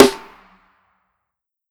Grammy Snare.wav